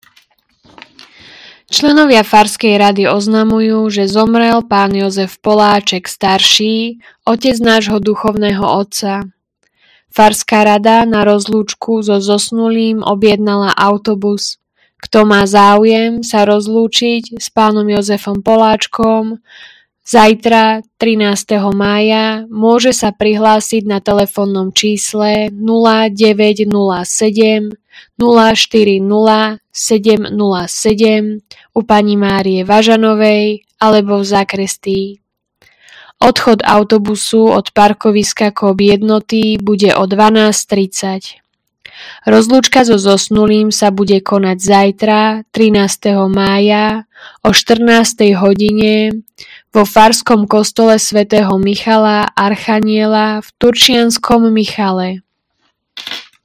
Hlásenie obecného rozhlasu